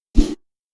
Melee Sword Sounds
Melee Weapon Air Swing 10.wav